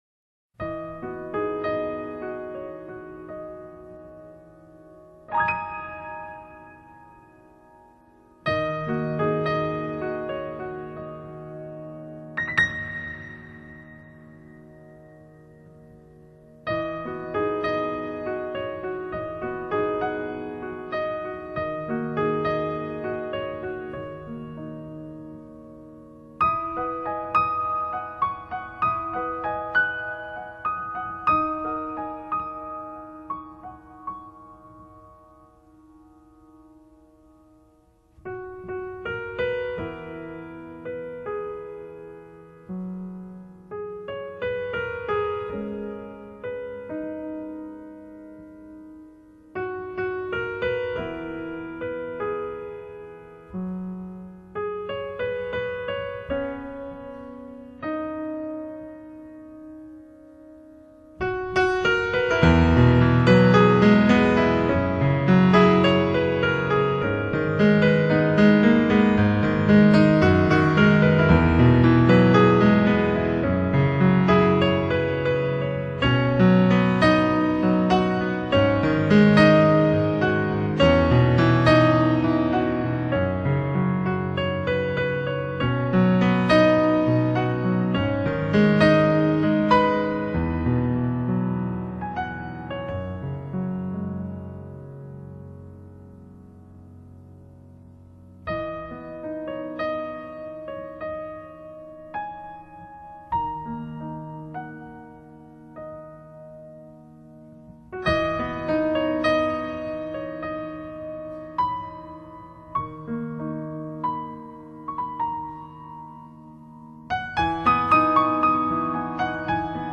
接下来将以四首纯钢琴作品，回归到New Age曲风上。